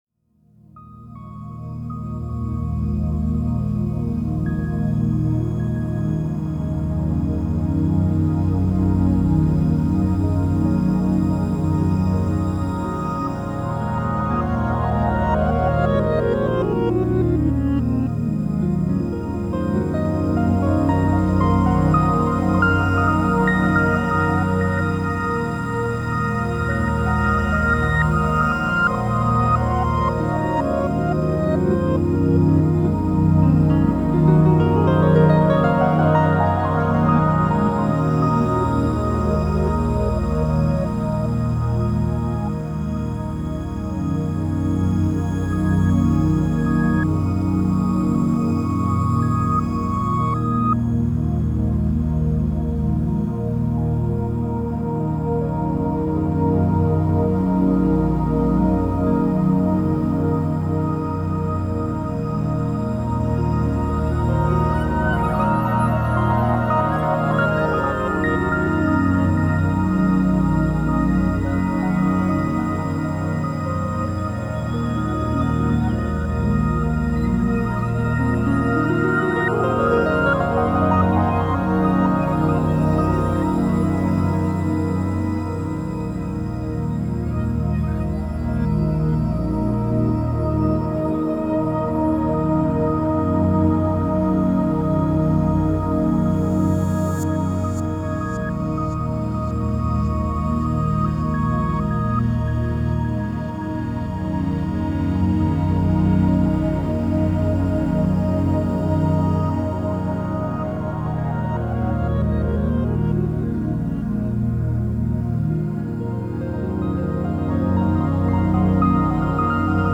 Genre: Psychill, Downtempo, IDM.